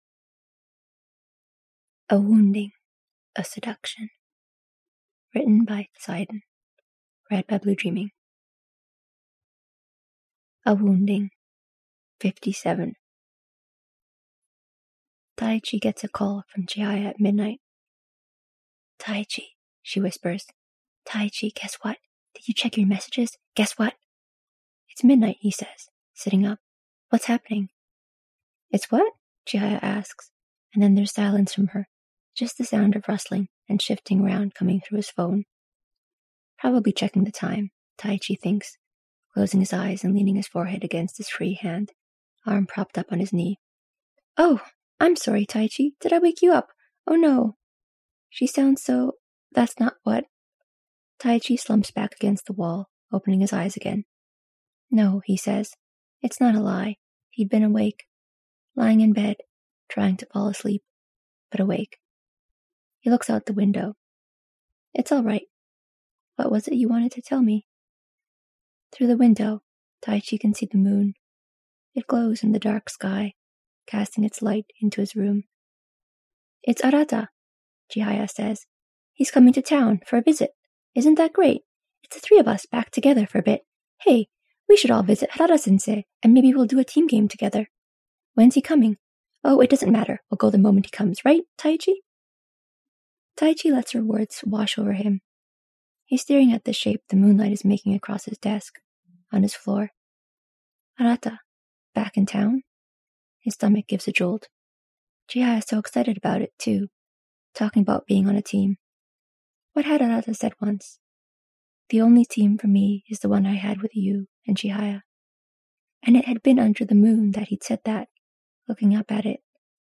no music: